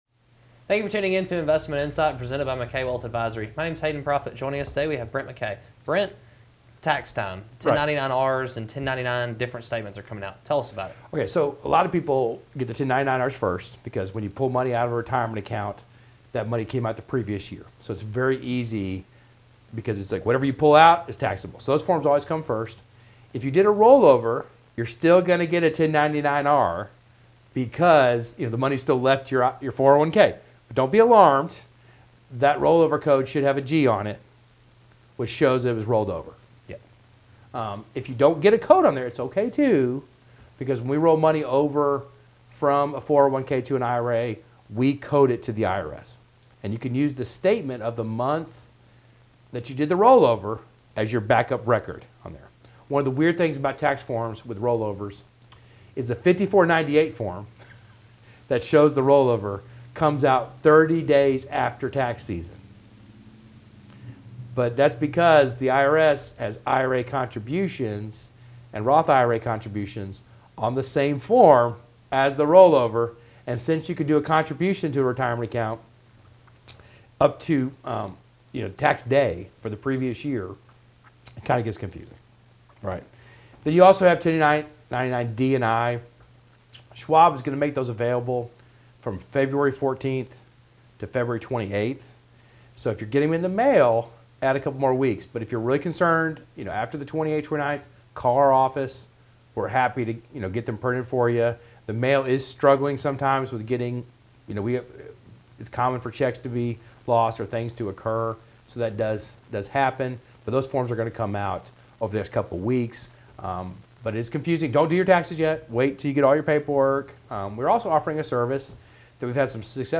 Hosts